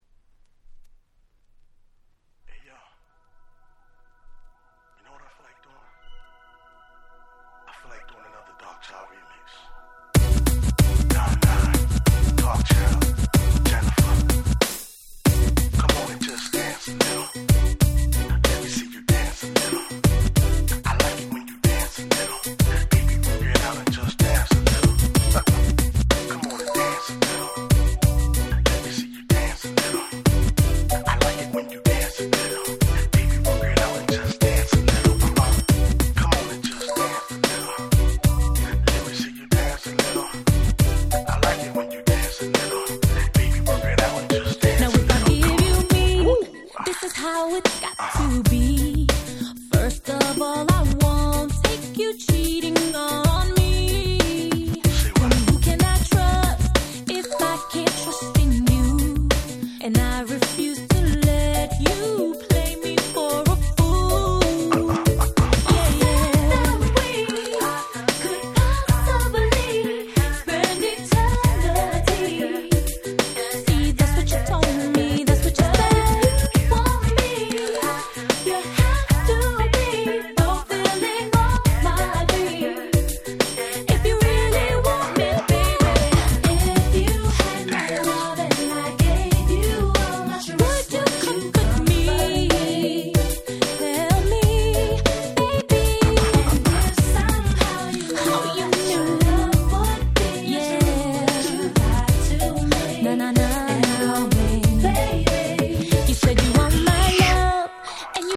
99' Super Hit R&B !!
なんとコレが今で言ういわゆる「オートチューン」だったそうな。